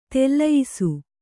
♪ tellayisu